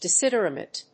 音節de・sid・er・a・tum 発音記号・読み方
/dɪsìdərάːṭəm(米国英語)/